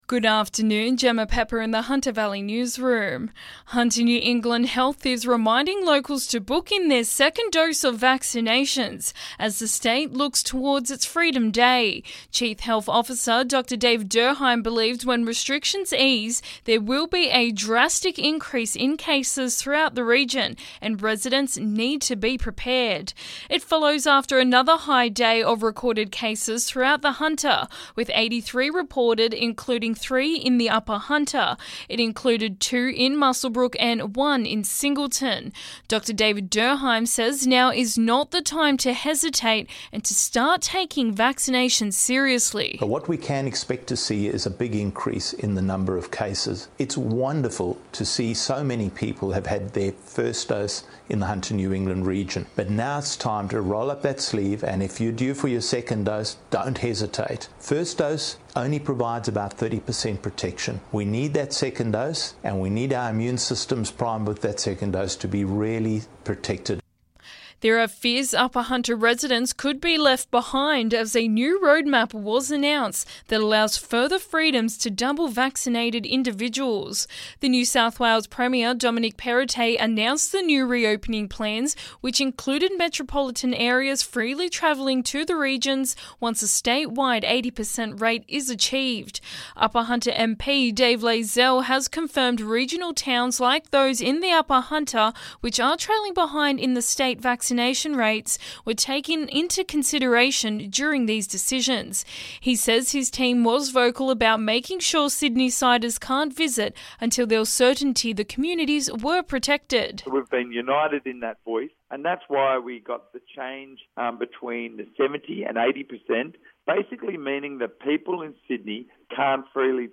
Listen: Hunter Local News Headlines 07/10/2021